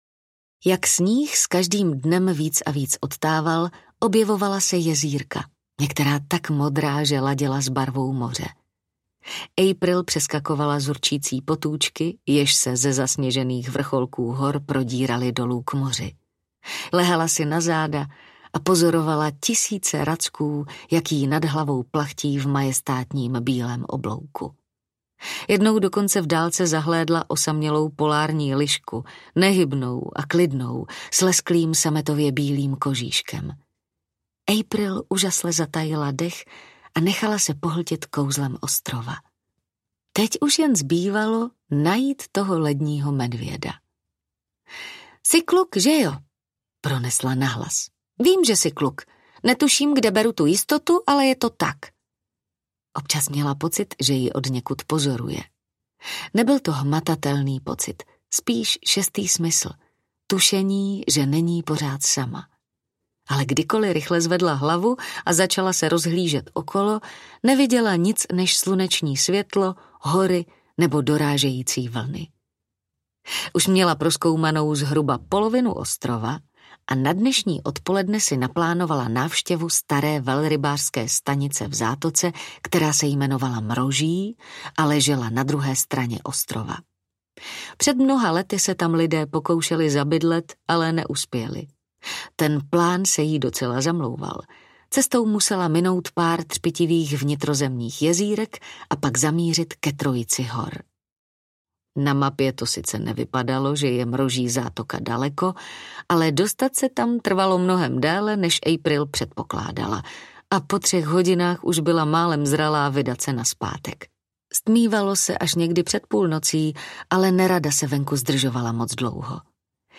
Poslední medvěd audiokniha
Ukázka z knihy